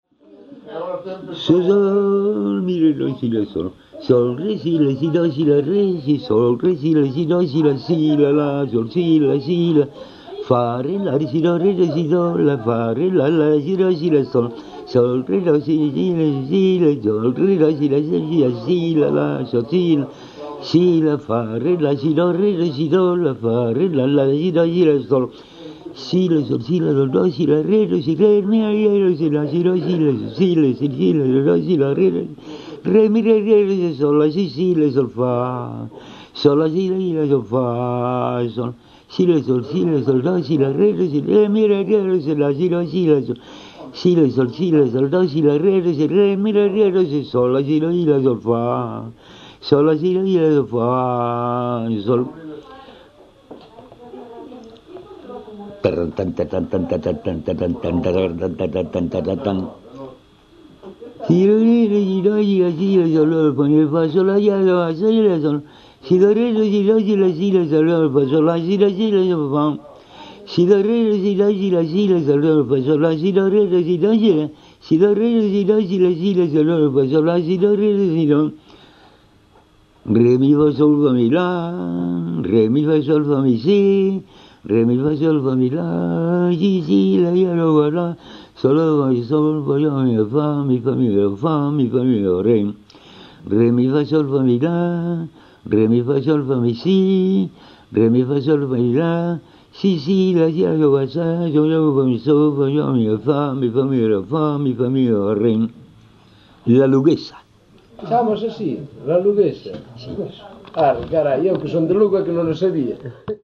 Palabras chave: solfexo
Tipo de rexistro: Musical
LITERATURA E DITOS POPULARES > Cantos narrativos
Soporte orixinal: Casete